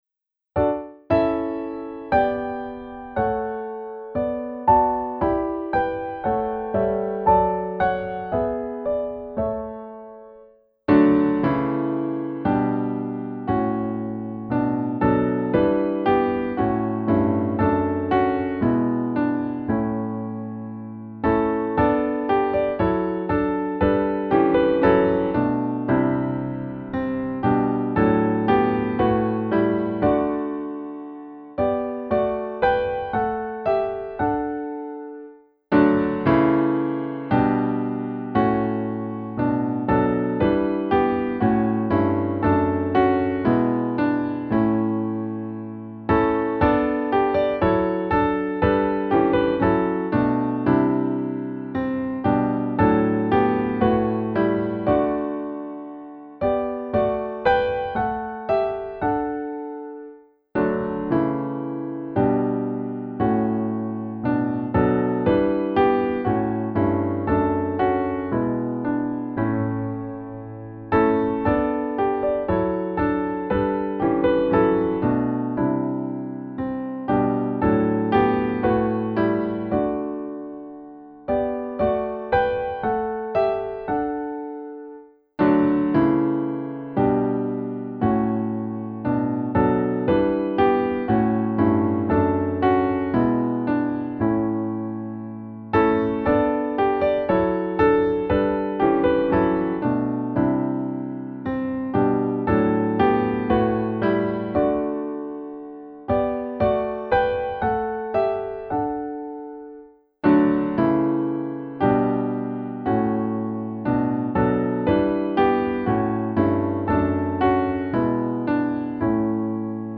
HYMN: Douglas Galbraith ©